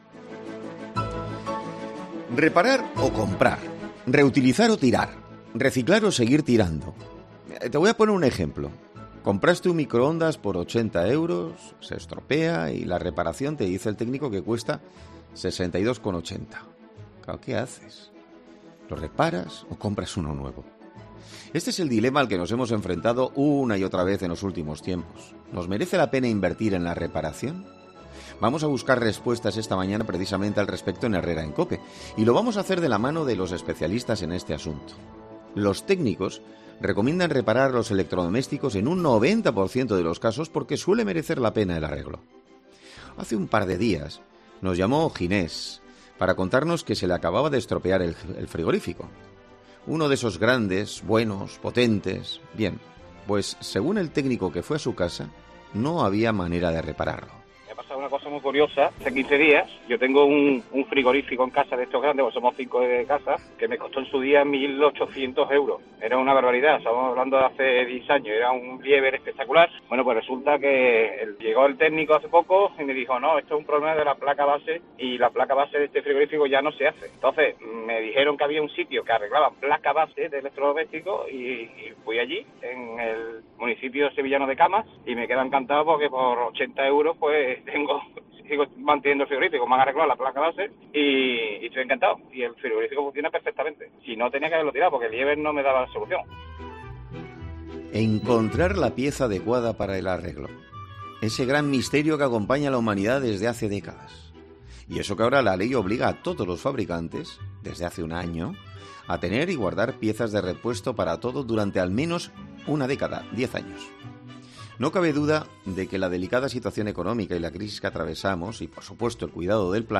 'Herrera en COPE' habla con el dueño de una tienda de reparación de electrodomésticos sobre la razón por la que se reparan menos de estos aparatos